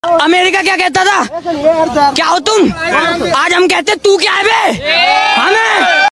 America Kya Kehta Tha? Hindi Questioning Meme Sound Effect
america-kya-kehta-tha-hindi-questioning-meme-sound-effect-b7f5d9c5.mp3